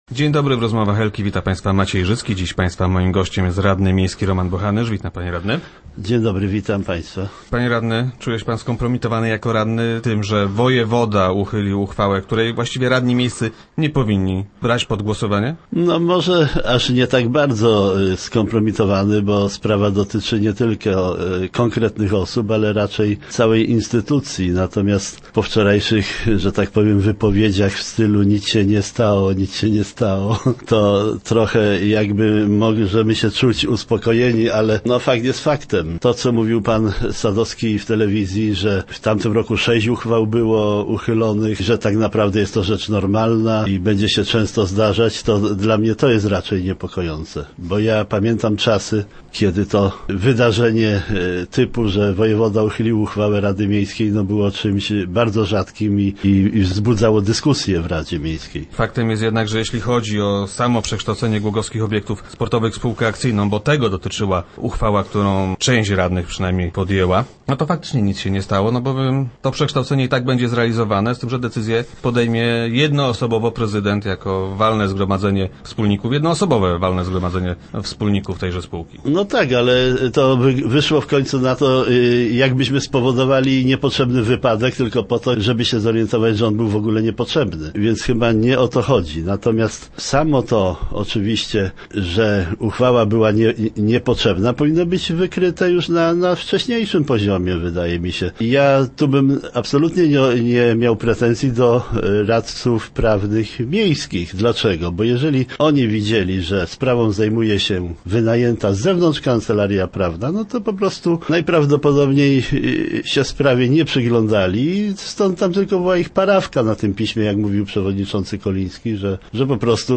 Jak twierdzi radny Roman Bochanysz, były także inne przesłanki do unieważnienia uchwały w tej sprawie. Samorządowiec był gościem czwartkowych Rozmów Elki.